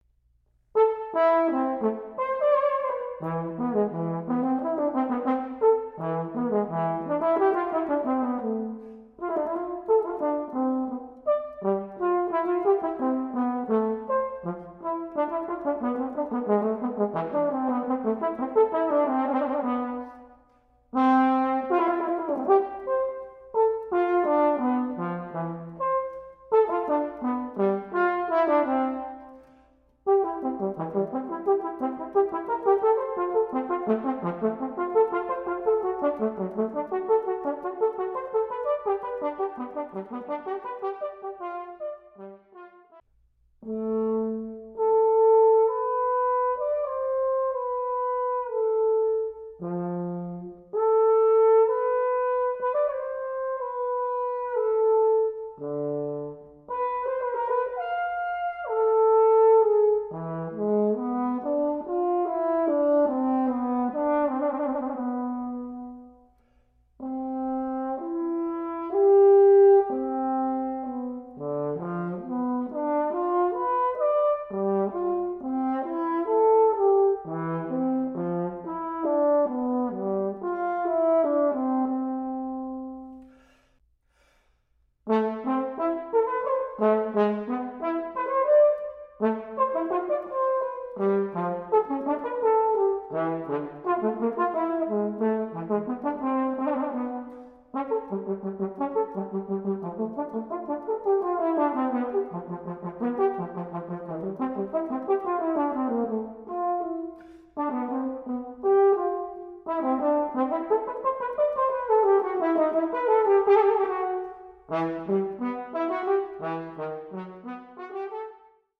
For Horn Solo
Unaccompanied